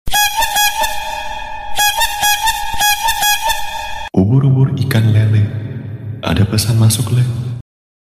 Genre: Nada notifikasi
Suaranya unik, kocak, dan pastinya bikin HP kamu makin rame.